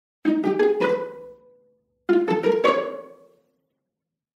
Âm thanh Bối rối, Tò mò
Âm thanh Tò mò, Tìm kiếm, Bối rối Nhạc nền Mở đầu Vui nhộn cho phim ảnh
Thể loại: Hiệu ứng âm thanh
am-thanh-boi-roi-to-mo-www_tiengdong_com.mp3